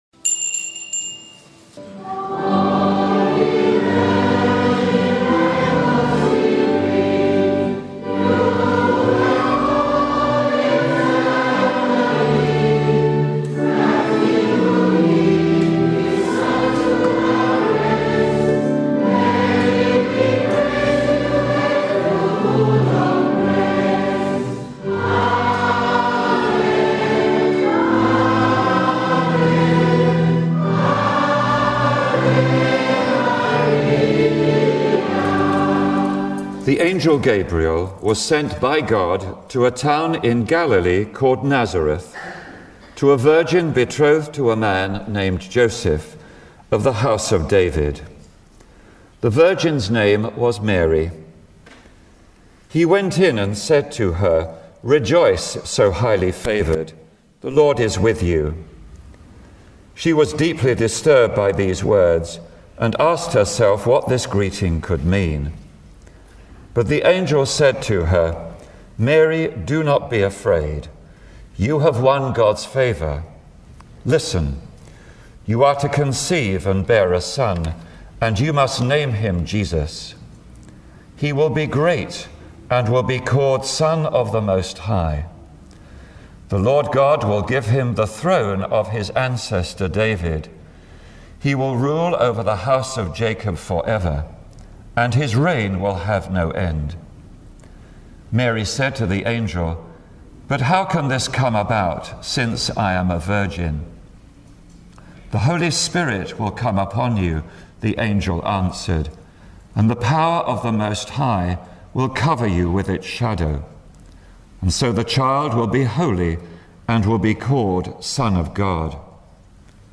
A Day With Mary (9.45am-5.15pm), Catholic Church of Mary, Mother of God, Ponders End, London.